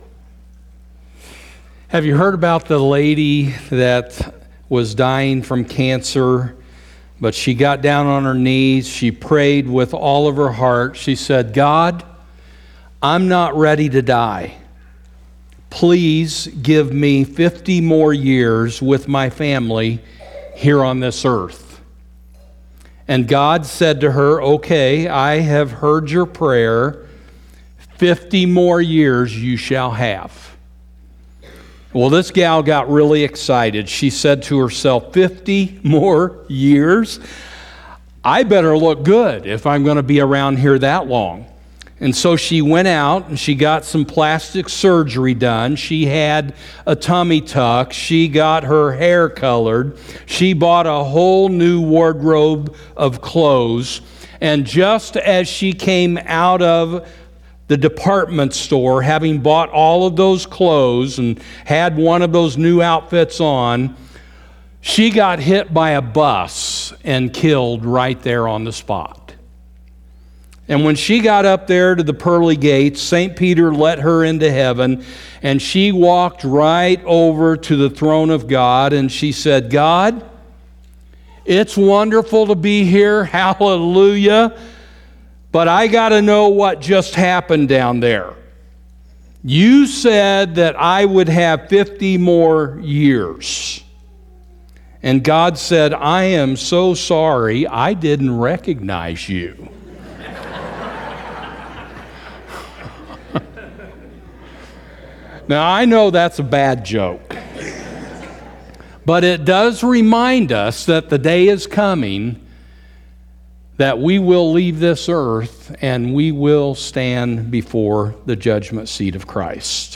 Sermons - Rinehart Church